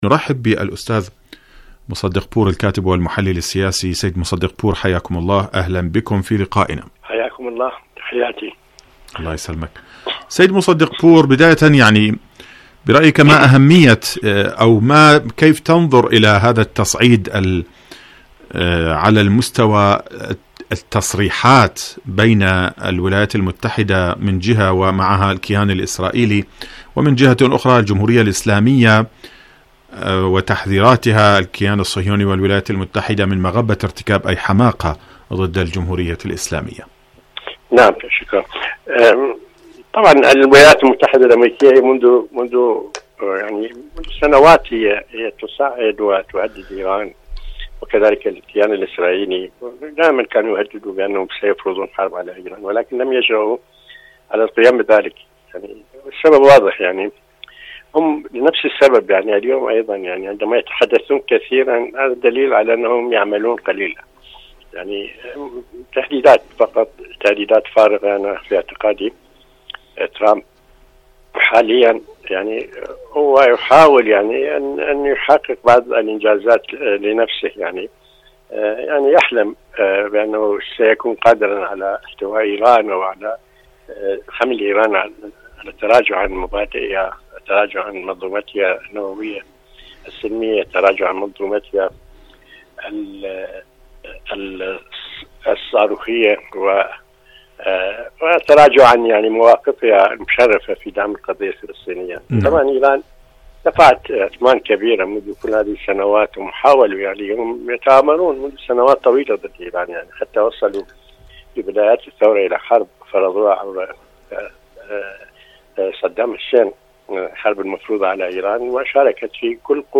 برنامج حدث وحوار مقابلات إذاعية